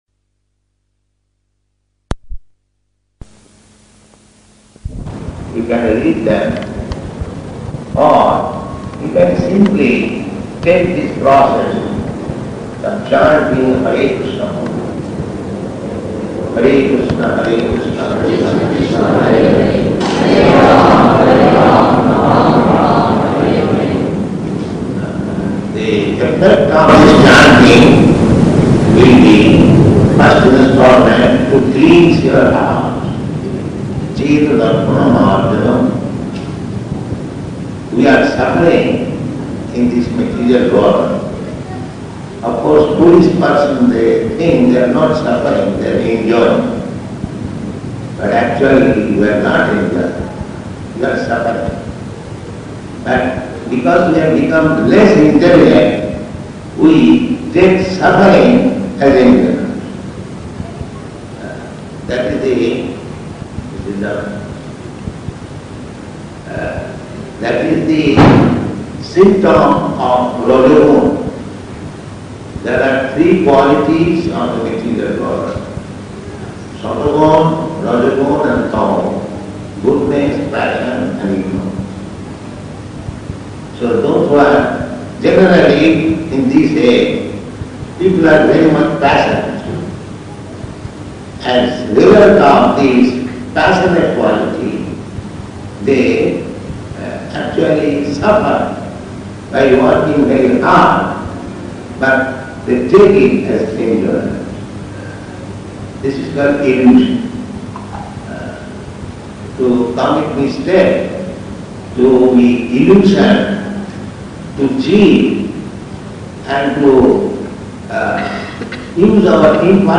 Lecture
Type: Lectures and Addresses
Location: Dallas